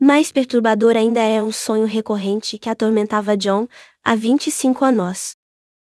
Áudios Gerados - Genesis TTS